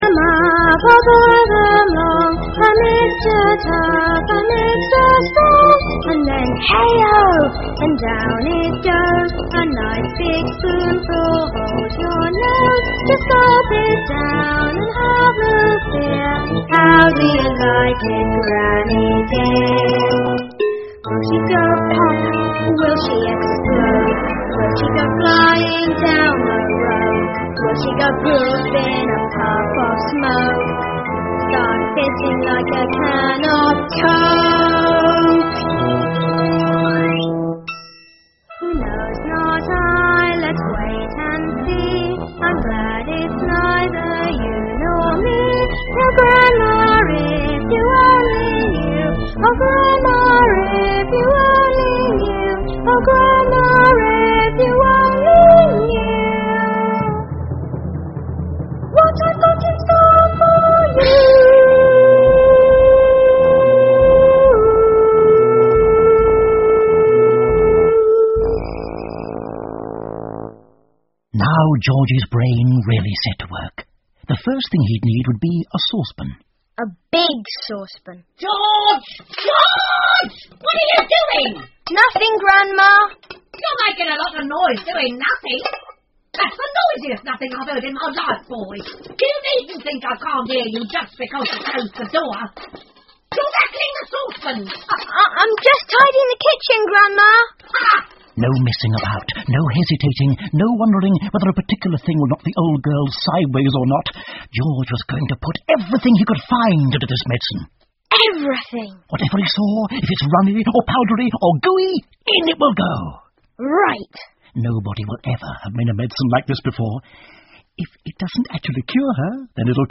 乔治的神奇药水 George's Marvellous Medicine 儿童广播剧 4 听力文件下载—在线英语听力室